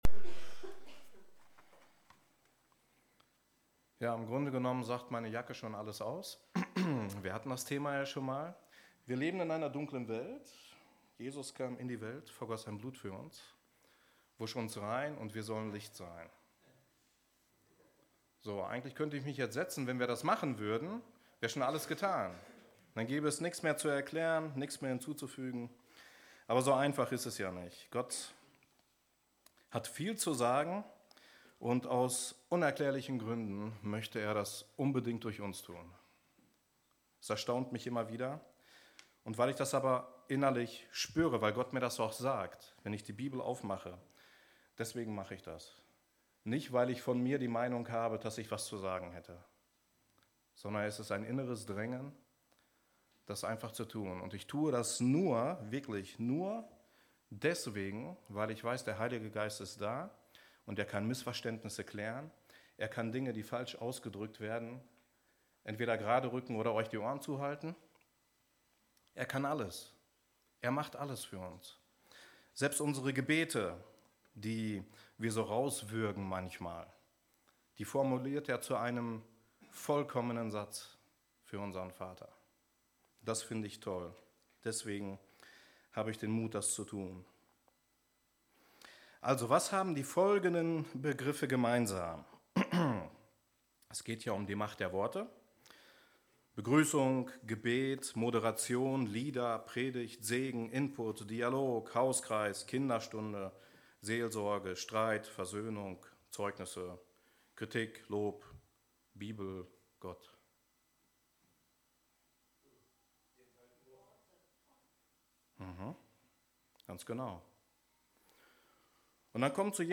Predigt vom 20. Oktober 2019 – efg Lage
Gottesdienst